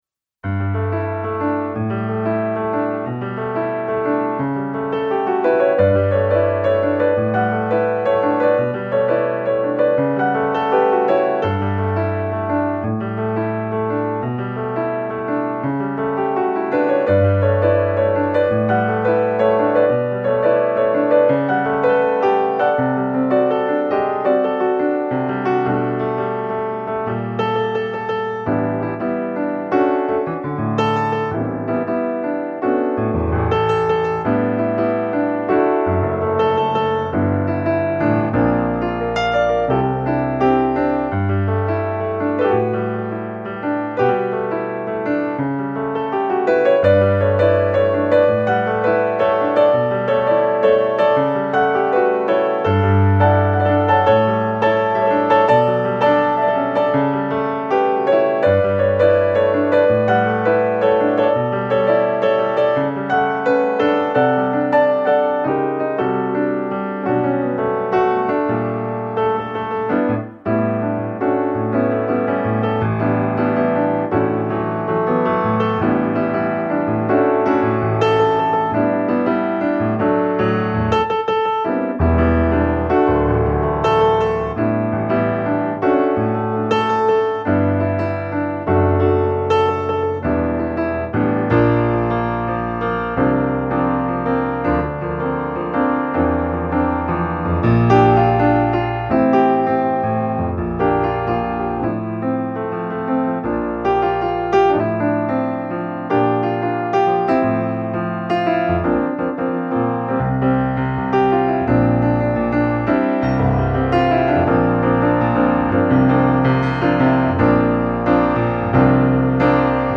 Solo Jazz and Contemporary Pianist for Hire